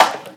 VEC3 Percussion 063.wav